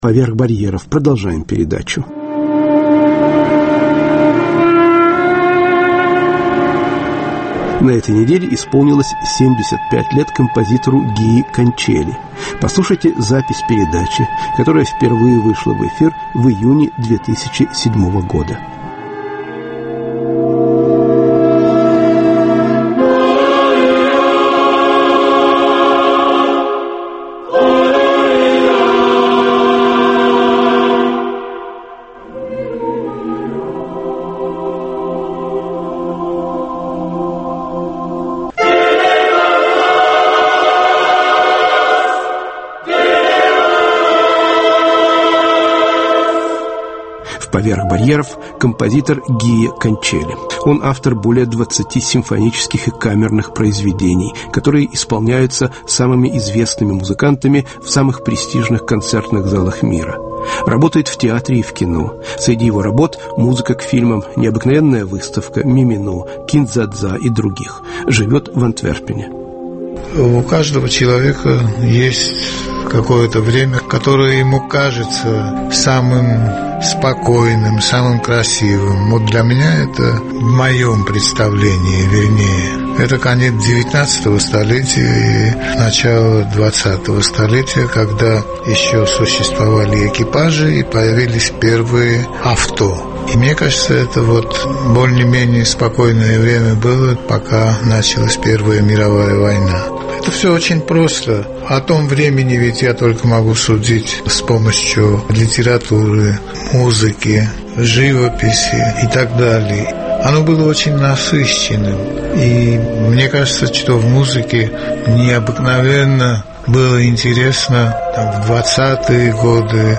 Гии Канчели - 75 лет: монологи и музыка композитора.